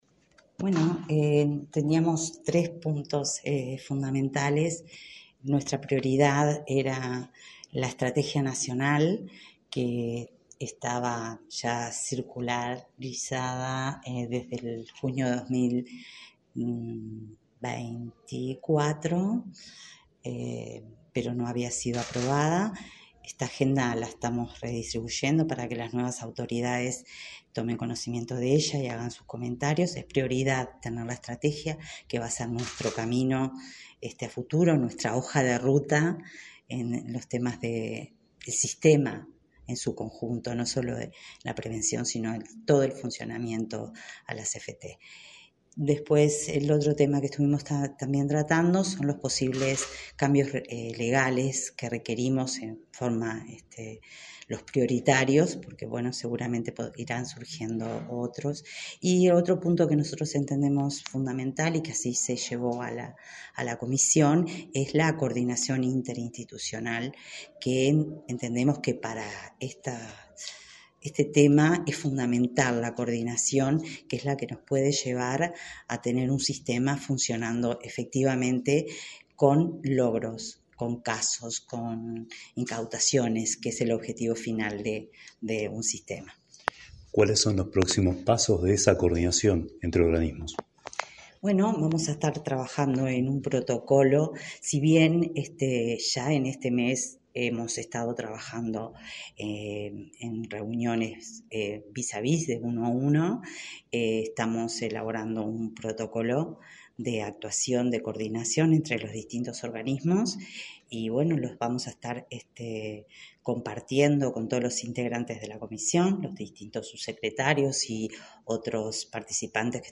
Entrevista a la titular de la Senaclaft, Sandra Libonatti
La secretaria nacional de Lucha contra el Lavado de Activos y Financiamiento de Terrorismo (Senaclaft), Sandra Libonatti, dialogó con Comunicación